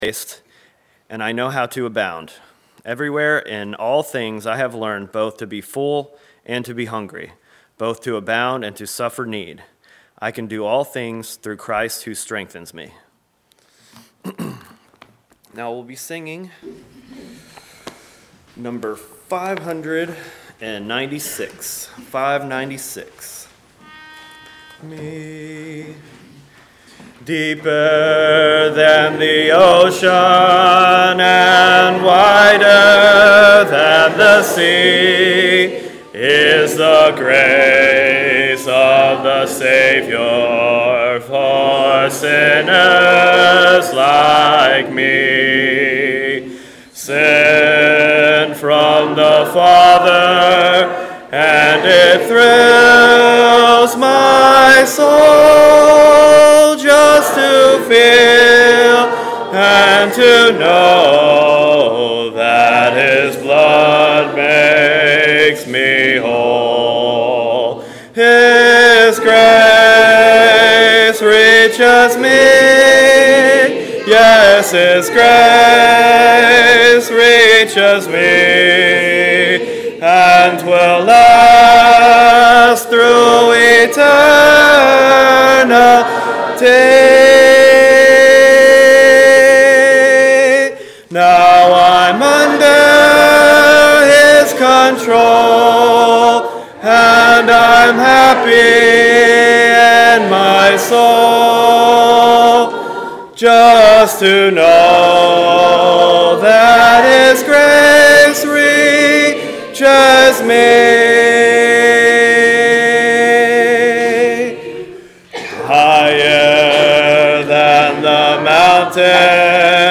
Sun PM Sermon -5th Sunday Night Sing – 29 September 2024